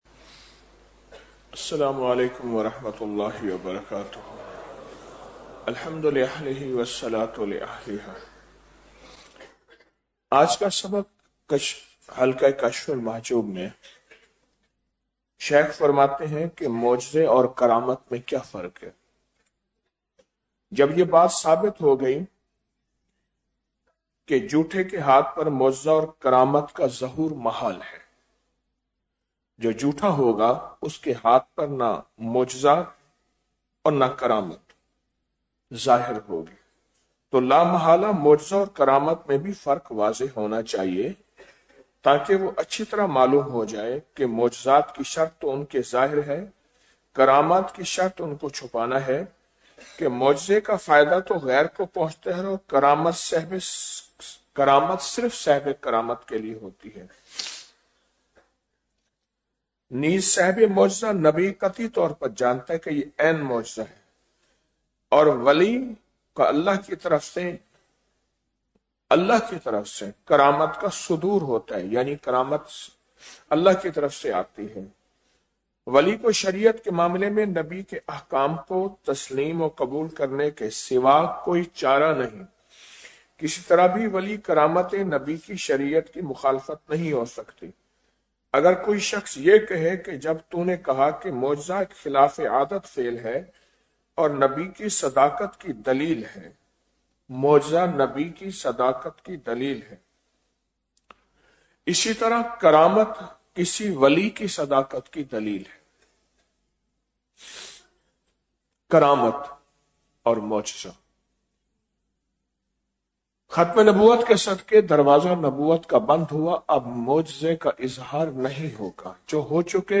مشاہدہ حق میں فنائیت -11 رمضان المبارک بعد نماز فجر- 01 مارچ 2026ء